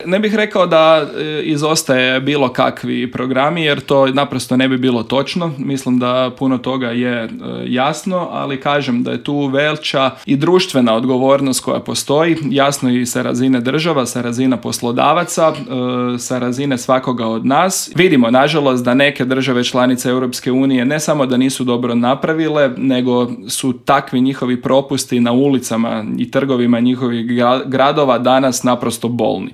Više detalja o Paktu, kako će se odraziti na Hrvatsku, ali i o kritikama na postignuti dogovor u intervjuu Media servisa razgovarali smo s eurozastupnikom iz redova HDZ-a, odnosno EPP-a Karlom Resslerom.